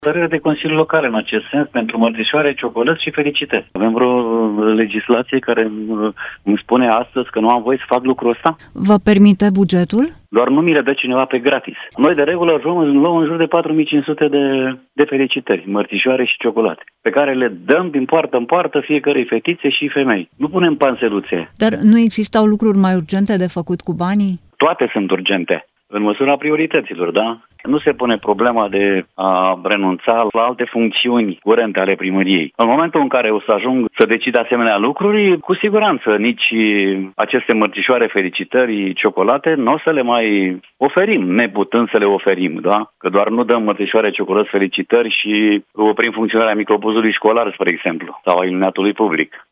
Aproximativ 100.000 de lei din bugetul local al comunei Dragomirești, județul Dâmbovița, vor fi folosiți în acest an pentru cadourile de 1 și 8 martie, din care 10.000 de euro numai pentru mărțișoare, scrie Digi24. Primarul PSD Dragoș Vlădulescu, care se află la al șaselea mandat, spune, contactat de Europa FM, că nici în an de criză financiară nu renunță la această, deja, tradiție.